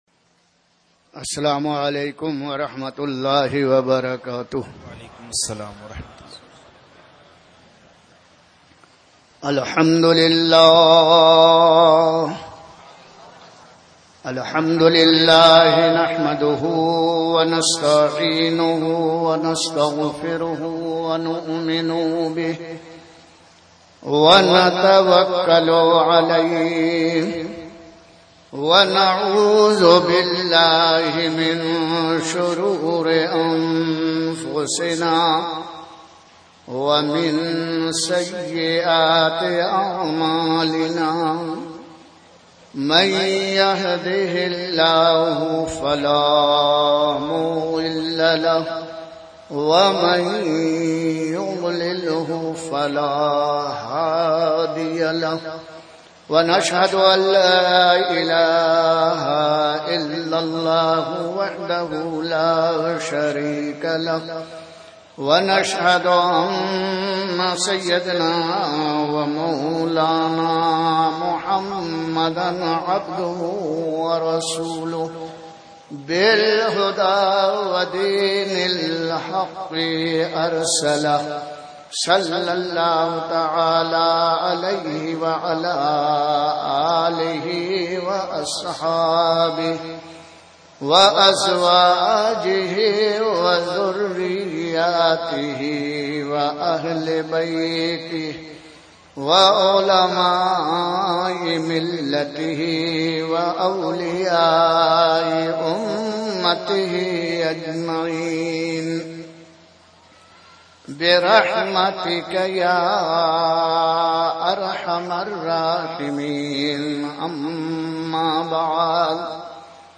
Speeches
Dastar-Gulshan-E-Razvi-Raichur2019 Jamia-Raza-E-Mustafa, Raichur, Karnataka